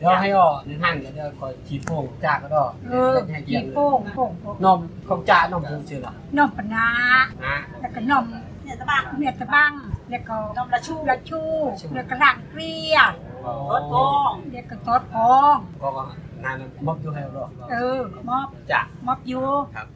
2 April 2023 at 11:37 pm Sounds east or southeas Asian.